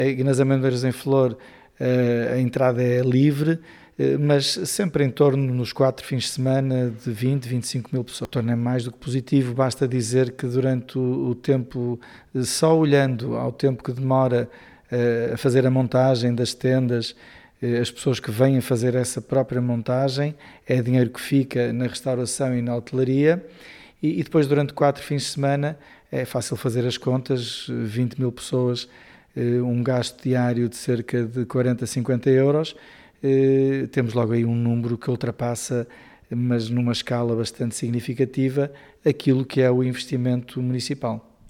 O presidente da Câmara Municipal de Vila Flor, Pedro Lima, sublinha que o evento pretende valorizar o território, a cultura e os produtos locais: